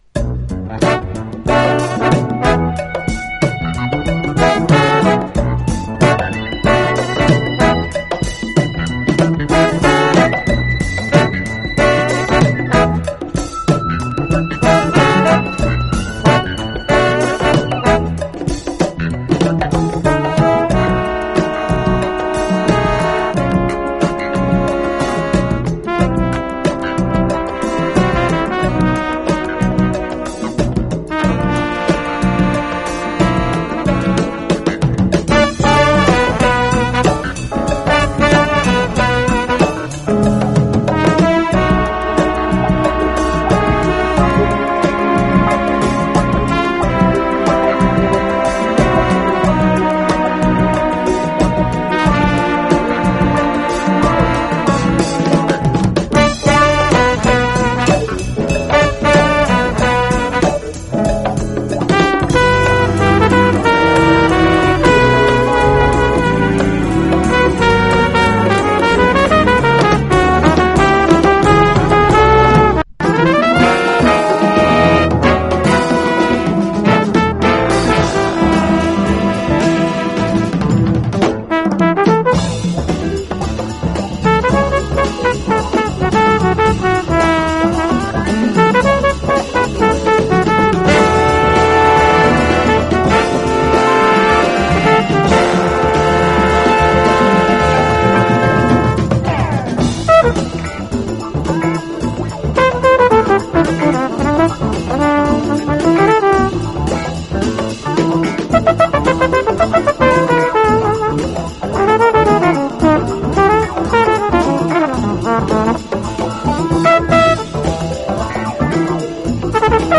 JAZZ / DANCEFLOOR / JAZZ FUNK
ヒンヤリとしたクールネスが際立ってるメロウ・ジャズ・ファンク人気盤！